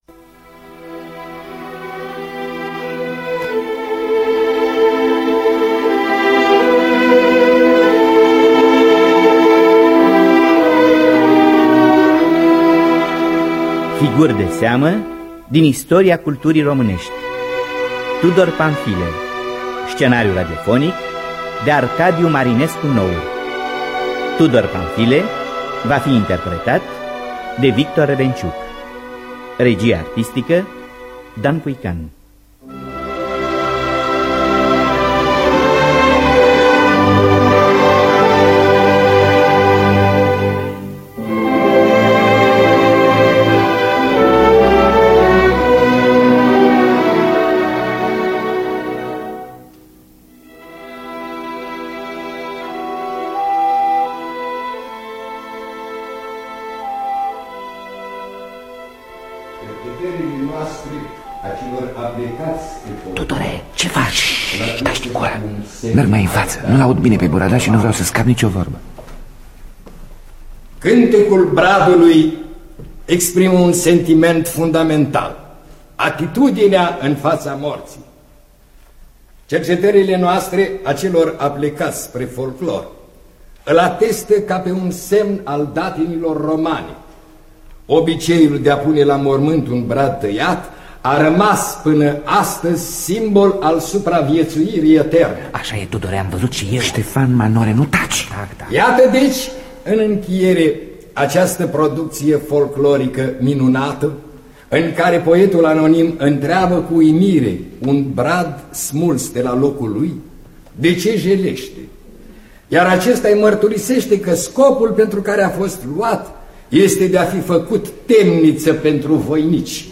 Scenariu radiofonic de Arcadiu Marinescu Nour.